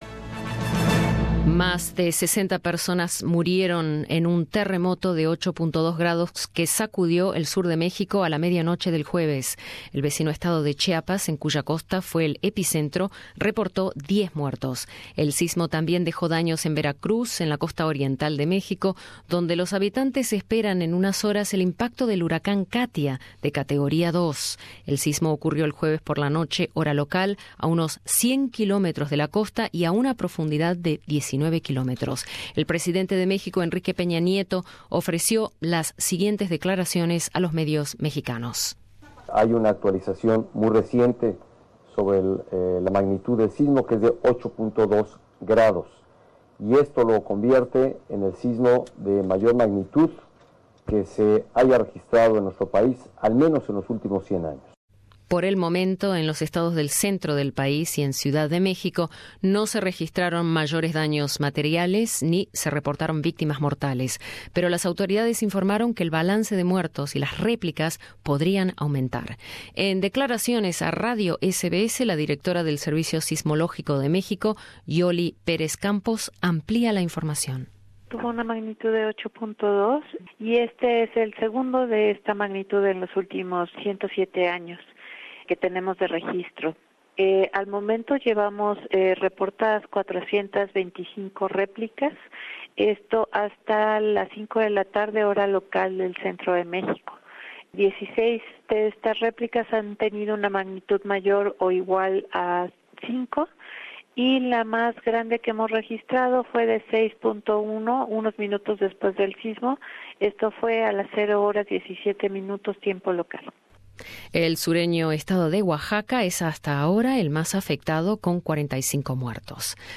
La población mexicana se prepara para recibir al huracán Katia después de ser sacudida por terremoto. Escucha nuestro informe: